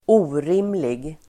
Uttal: [²'o:rim:lig]